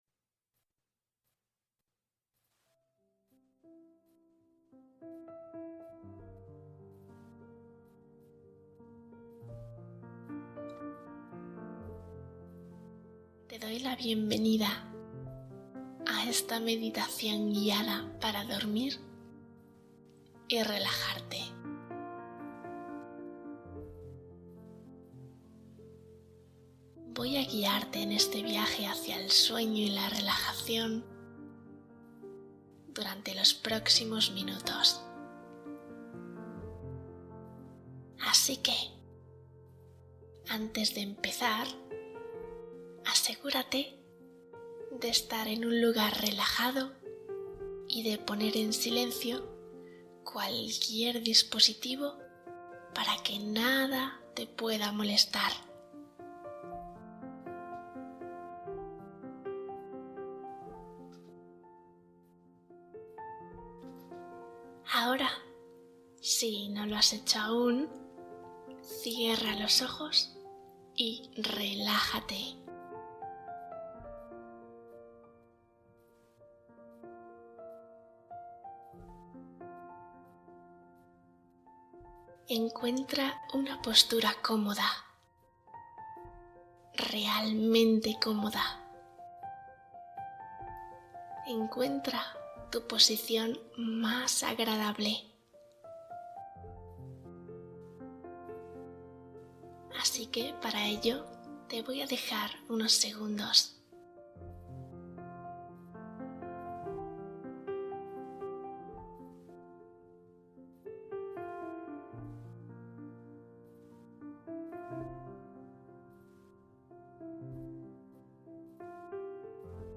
Meditación para dormir y calmar la ansiedad nocturna